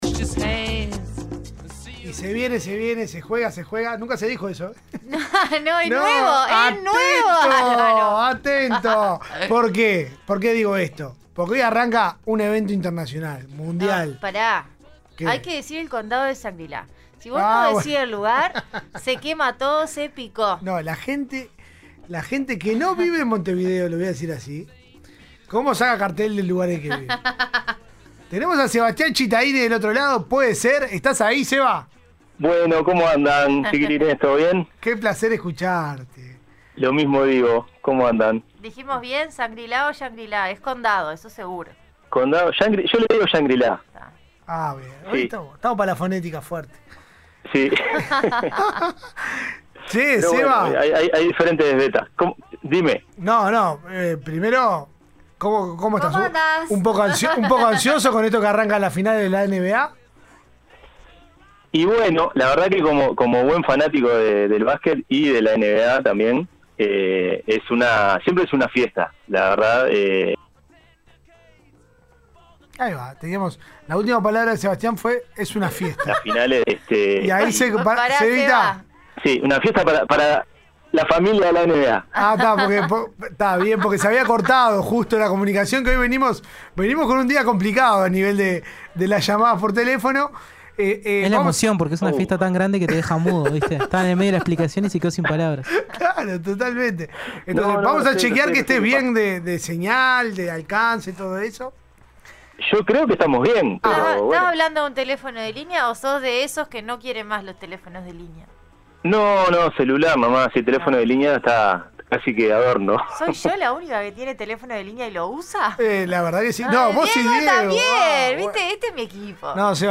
Zona Mixta: entrevista